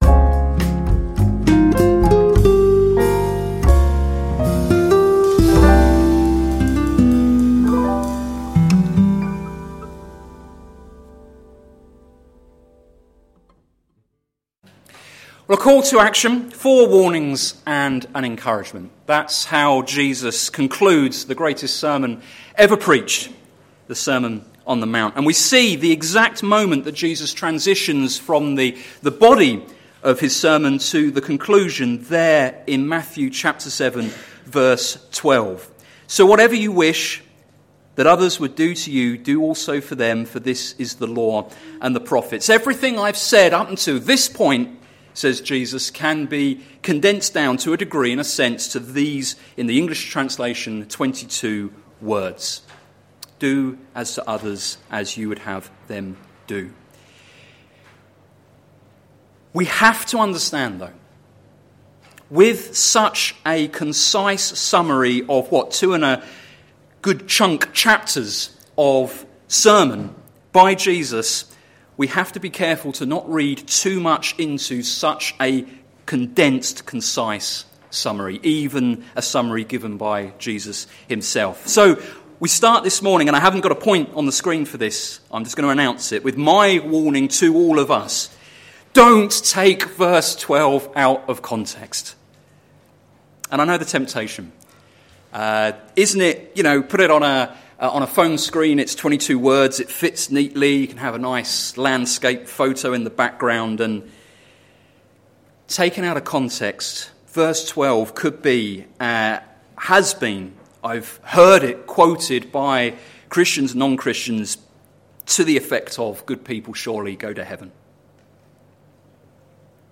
Sermon Series - Things Jesus said - plfc (Pound Lane Free Church, Isleham, Cambridgeshire)